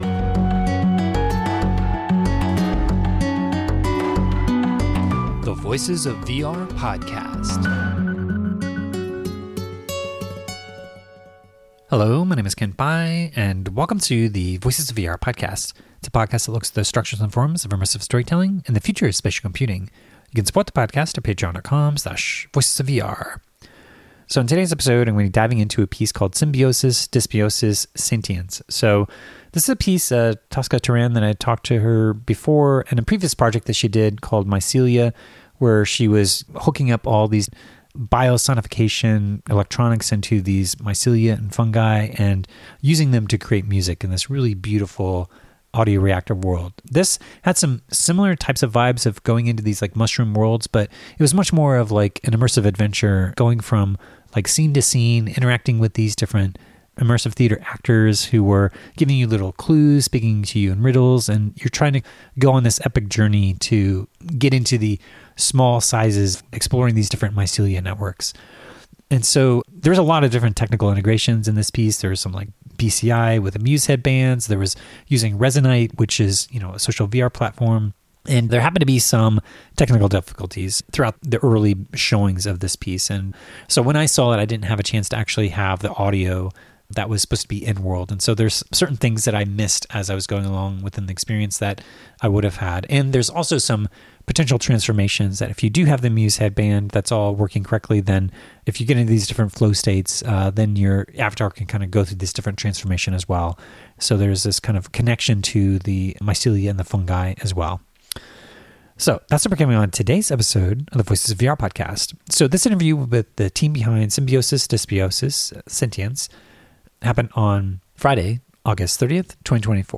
I interviewed Symbiosis
at Venice Immersive 2024